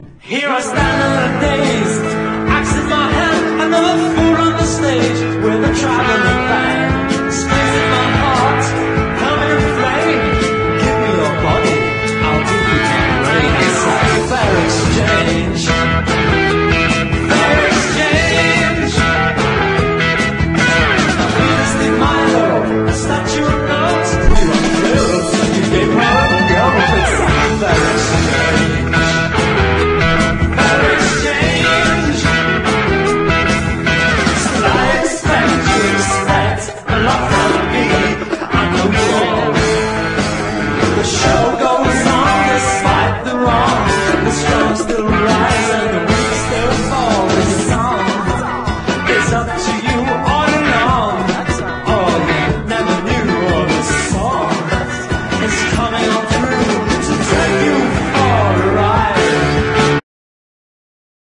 CITY POP / DRUM BREAK